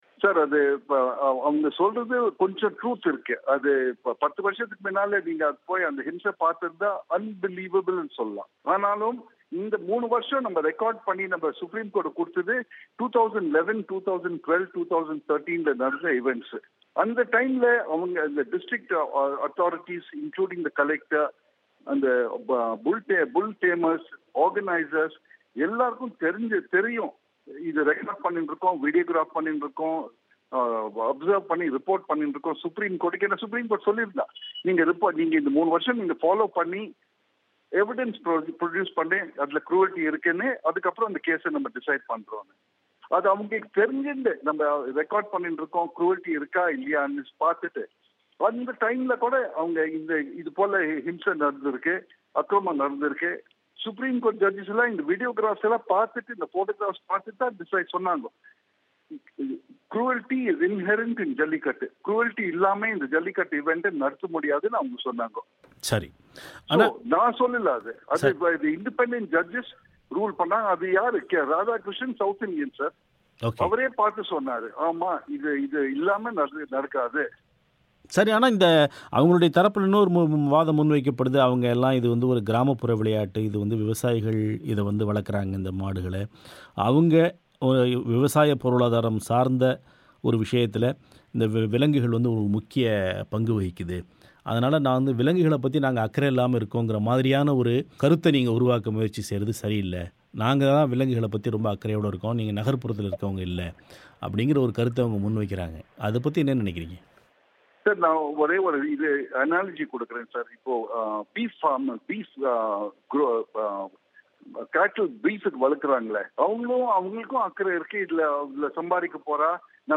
இந்த வாதம் சரியானதுதான என்று ஜல்லிக்கட்டுக்கு எதிராக வழக்குத் தொடுத்த இந்திய விலங்கு நல வாரியத்தின் துணைத்தலைவர் சின்னிகிருஷ்ணா அவர்களிடம் பிபிசி தமிழோசை கேட்டபோது, இதில் கடந்த காலங்களை ஒப்பிடும்போது ஓரளவு முன்னேற்றம் இருந்திருக்கலாம் , ஆனால் உச்சநீதிமன்ற உத்தரவின் படி, தங்கள் குழு 2011லிருந்து 2013 வரை நடந்த ஜல்லிக்கட்டுப் போட்டிகளை வீடியோ பதிவு செய்த போது கூட பல சந்தர்ப்பங்களில் விலங்குகள் துன்புறுத்தப்பட்டது கண்கூடாகத் தெரிந்தது.